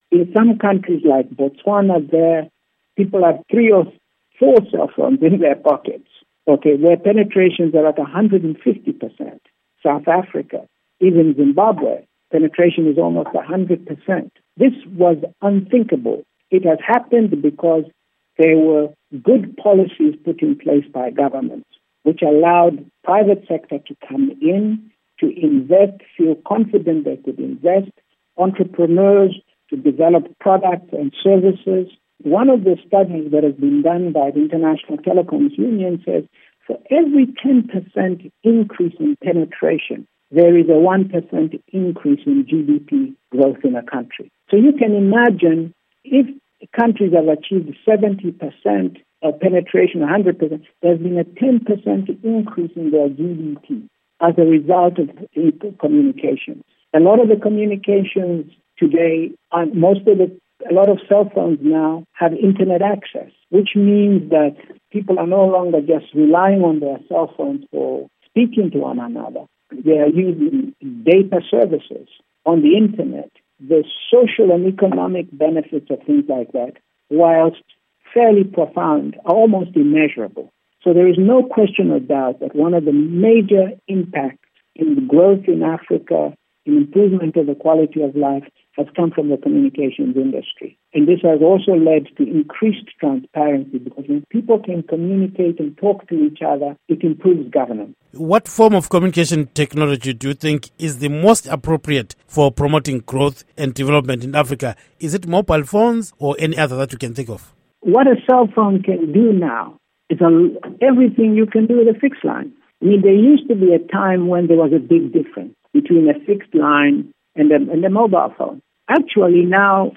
Interview With Strive Masiyiwa